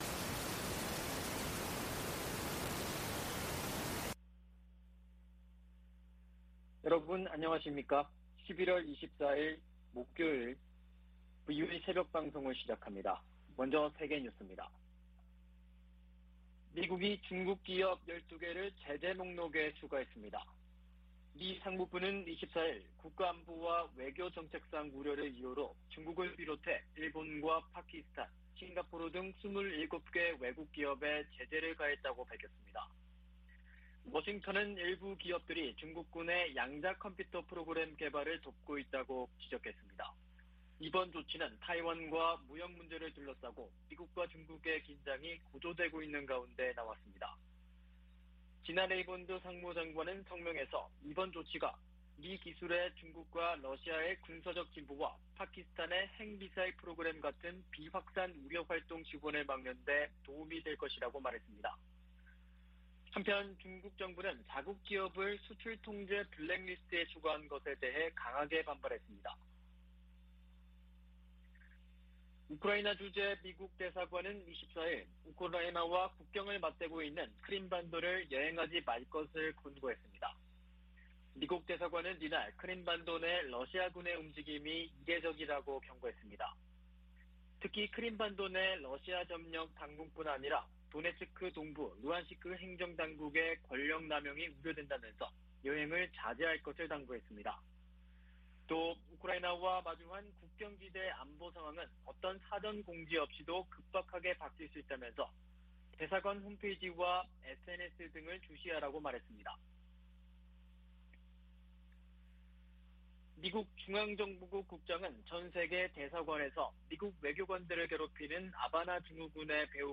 VOA 한국어 '출발 뉴스 쇼', 2021년 11월 26일 방송입니다. 국제원자력기구(IAEA)는 영변 핵 시설에서 새로운 활동이 관찰되고 평산과 강선의 관련 시설에서도 지속적인 활동 징후가 포착됐다고 밝혔습니다. 북한은 의도적 핵활동 노출로 미국을 압박하고 이를 협상력을 높이는 지렛대로 활용하려는 계산이라고 한국의 전문가가 분석했습니다. 미국과 한국의 한국전쟁 종전선언 논의가 문안을 마무리하는 단계에 있다고, 미국 정치 전문 매체가 보도했습니다.